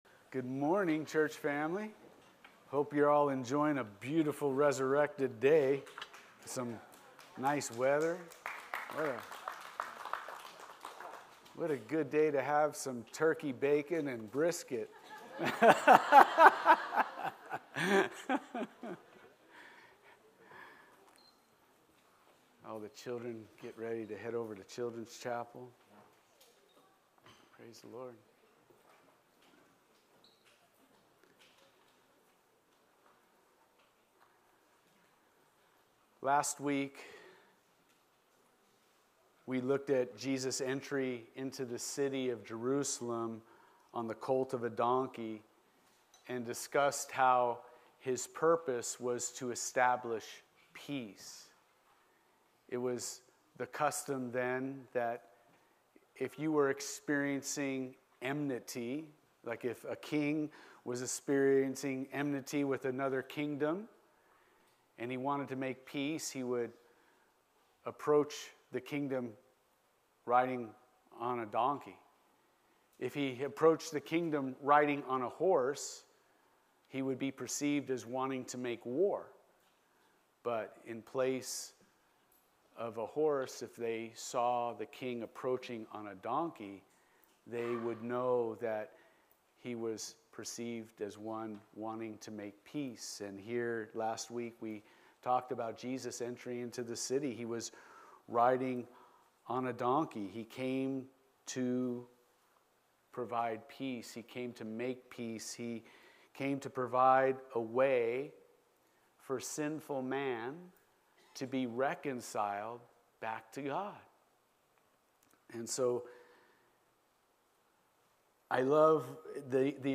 Sermons | Calvary Chapel Pahrump Valley